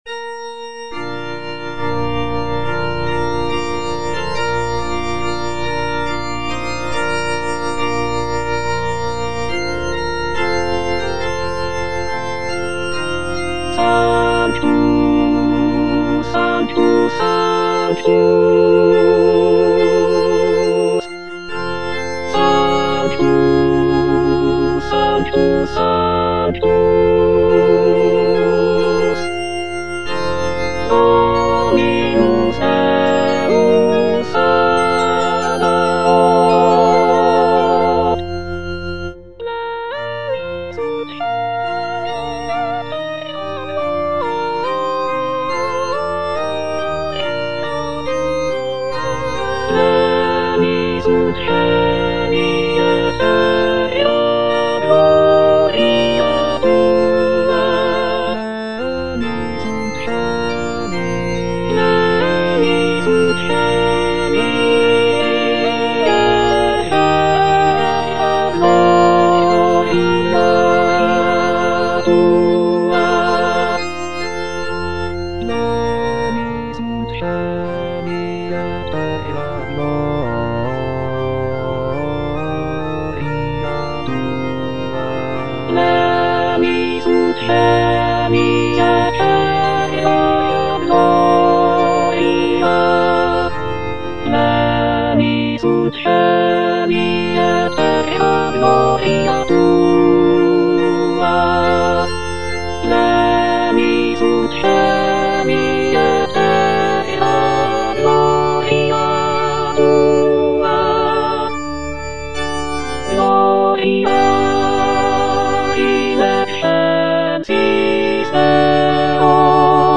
Alto (Emphasised voice and other voices) Ads stop
is a sacred choral work rooted in his Christian faith.